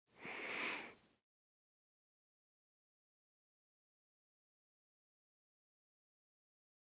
Inhale | Sneak On The Lot
Single, Light Inhale Through Nose